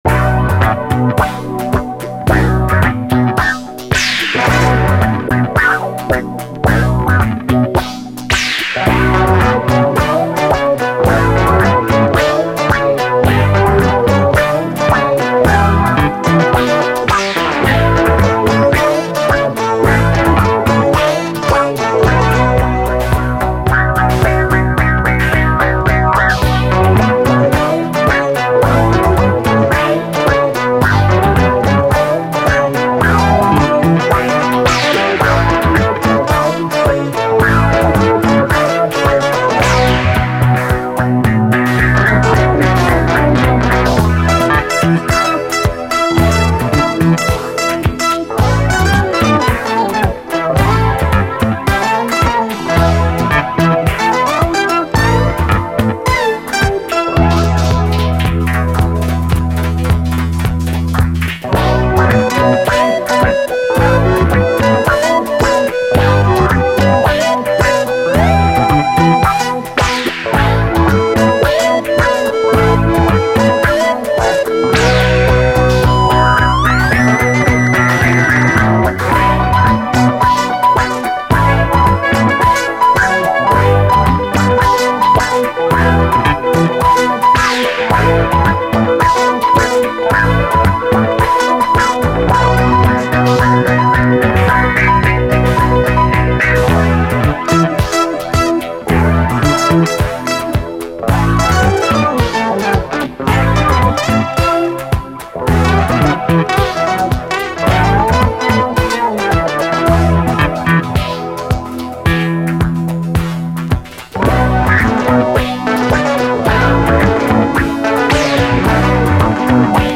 DISCO, 70's ROCK, ROCK, 7INCH
妖しさ満点のオランダ産マッド・メロウ・ディスコ45！